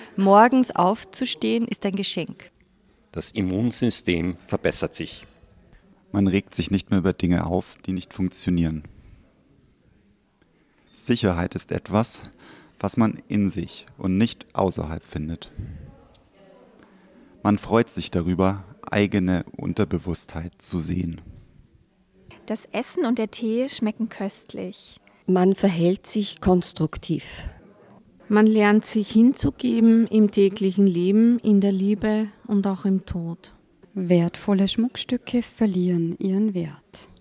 99 Gründe zum Meditieren mit Stimmen